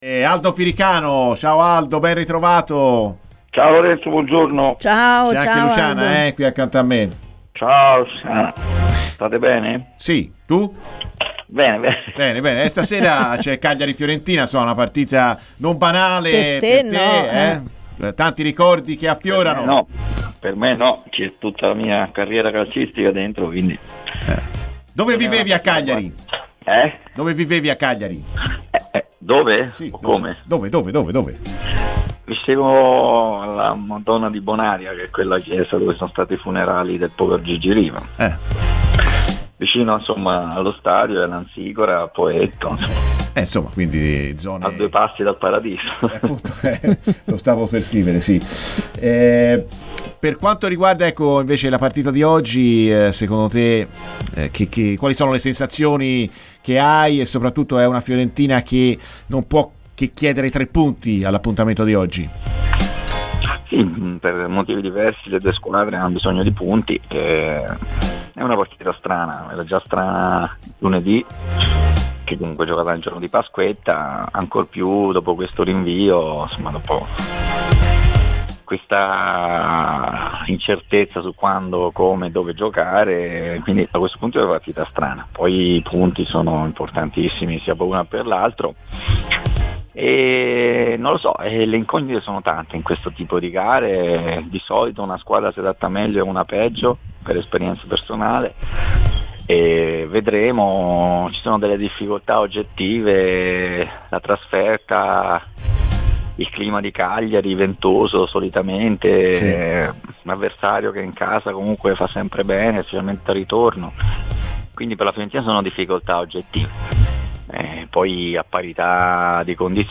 Ospite di Radio FirenzeViola, Aldo Firicano, con un grande passato (sette anni) a Cagliari, prima di approdare alla Fiorentina, ha parlato proprio.